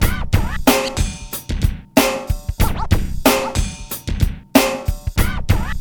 BEAT 9 93 08.wav